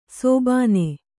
♪ sōbāne